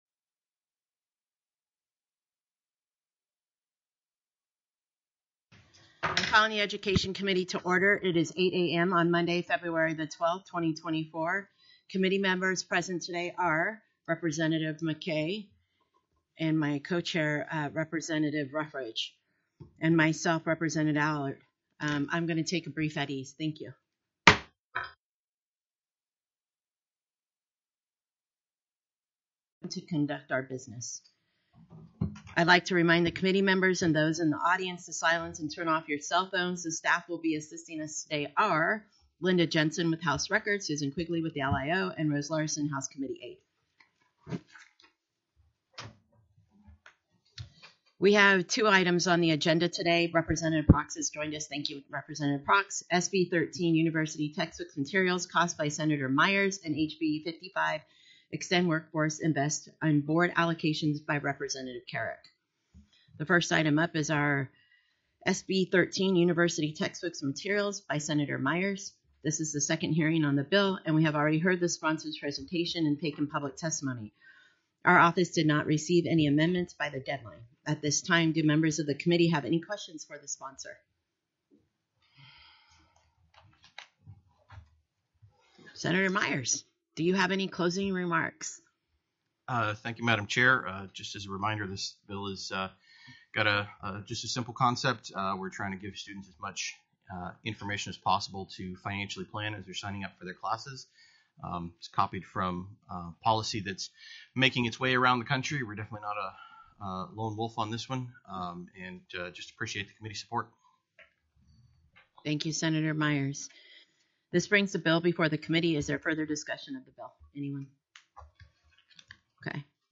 The audio recordings are captured by our records offices as the official record of the meeting and will have more accurate timestamps.
TELECONFERENCED
-- Public Testimony --